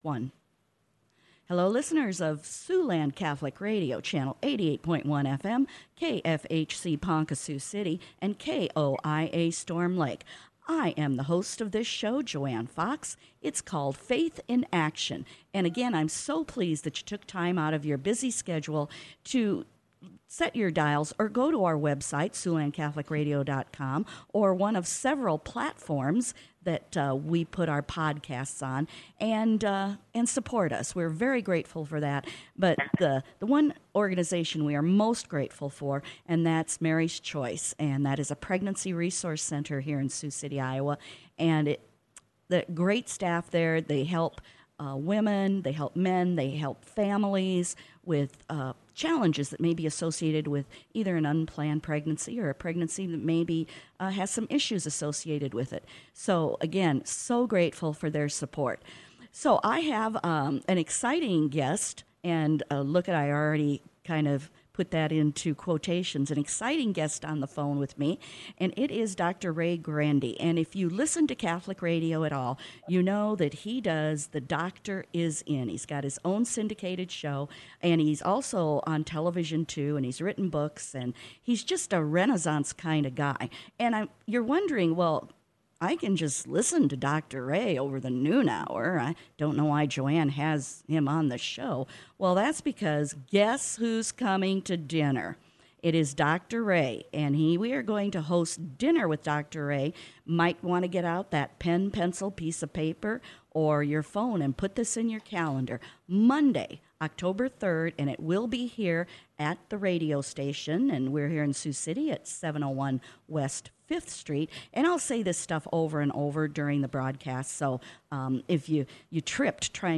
Exclusive Interview with Dr. Ray Guarendi - Sept. 19 and 24, 2022